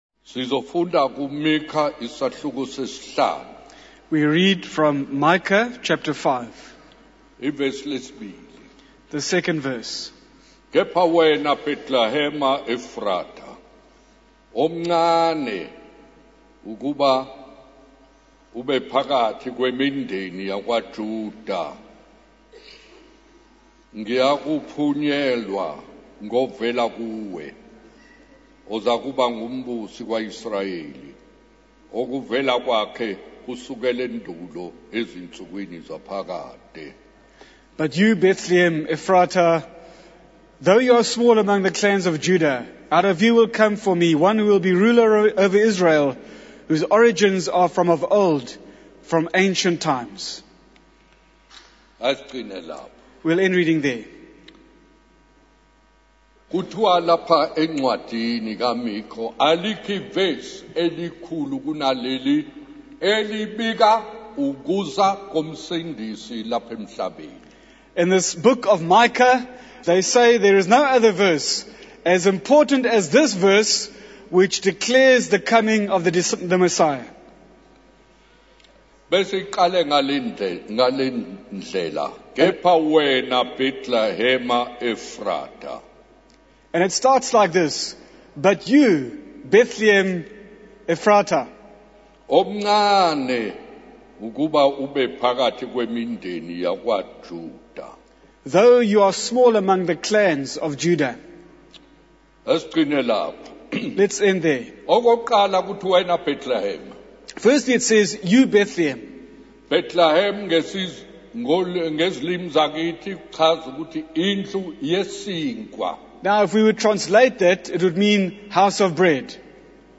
In this sermon, the speaker shares a story about a gathering of ministers, magistrates, and high-ranking officials in a luxurious setting.